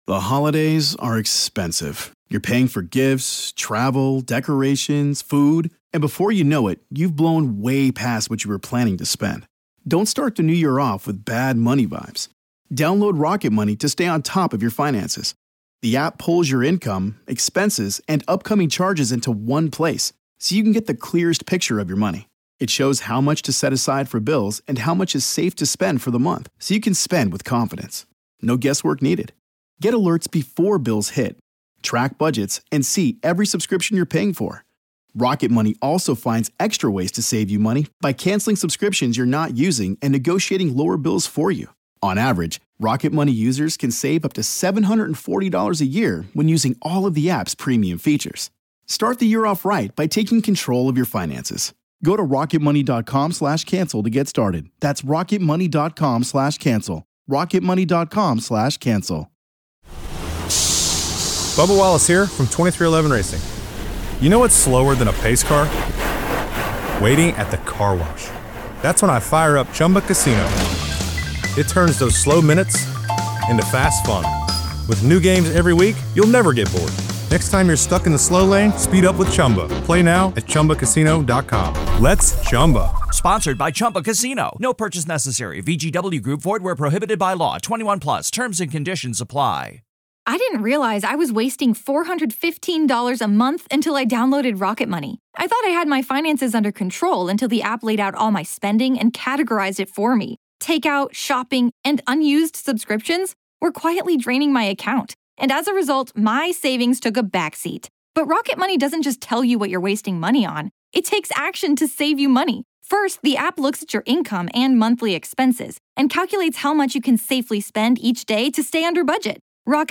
From the best supernatural & paranormal podcast, Real Ghost Stories Online! Haunting real ghost stories told by the very people who experienced these very real ghost stories.